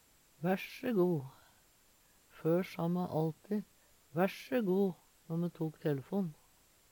Væsje go! - Numedalsmål (en-US)